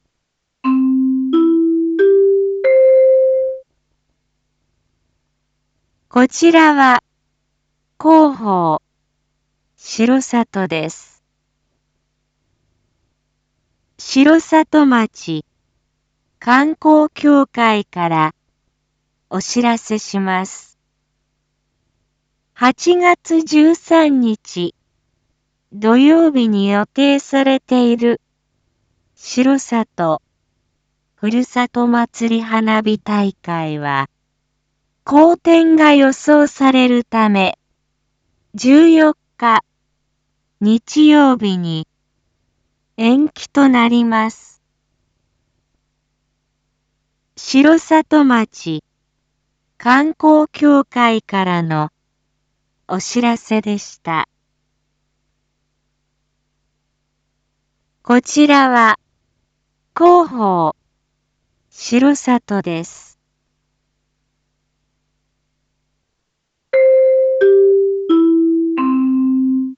一般放送情報
Back Home 一般放送情報 音声放送 再生 一般放送情報 登録日時：2022-08-12 19:01:11 タイトル：R4.8.12 19時放送分 インフォメーション：こちらは広報しろさとです。